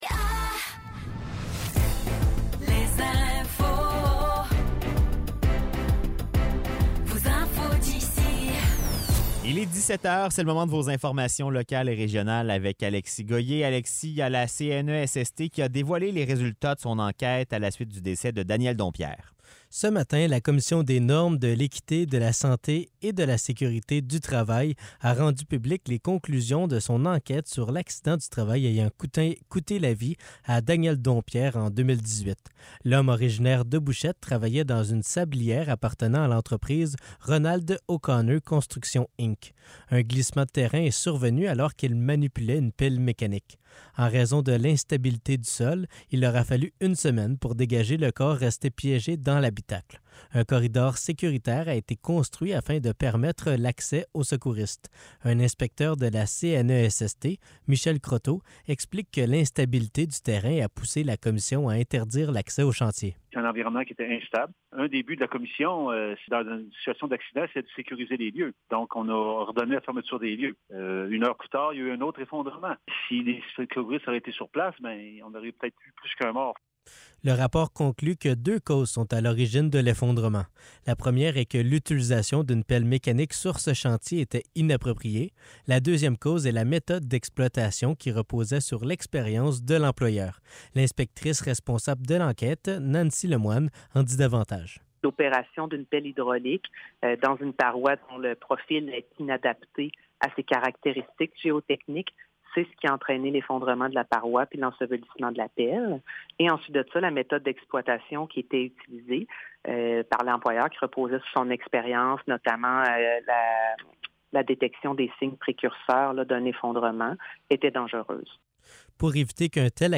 Nouvelles locales - 18 octobre 2023 - 17 h